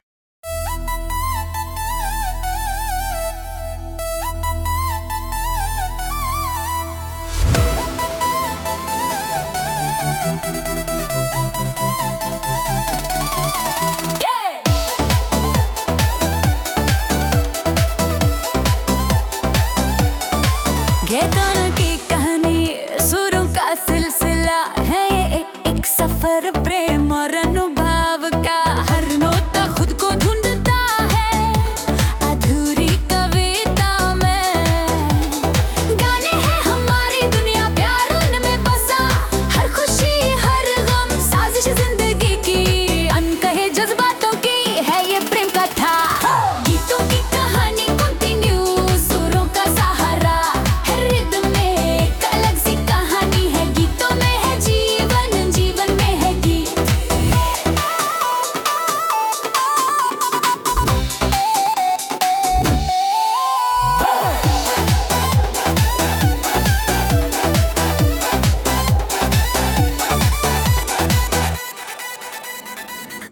Hindi Bubblegum Dance